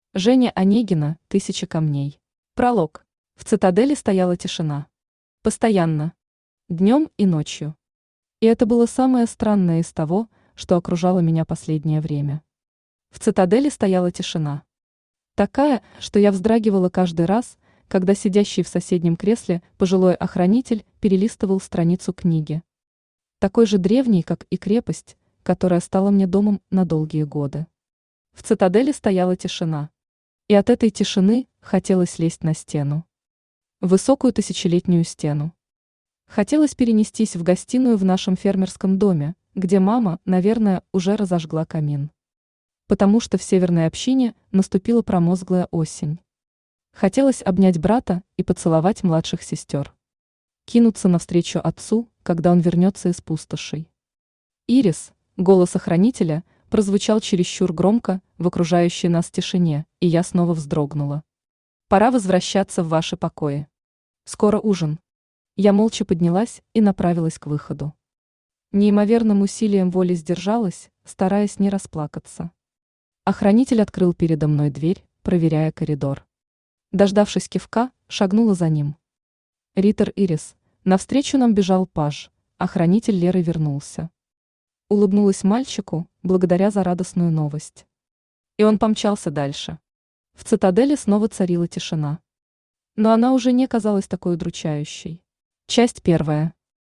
Aудиокнига Тысяча камней Автор Женя Онегина Читает аудиокнигу Авточтец ЛитРес.